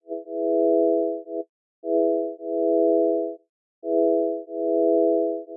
Phone Tones » Dreamer
描述：An interesting sound that would serve well as a text message alert for a smartphone.
标签： message call phone alert ringtone text tone
声道立体声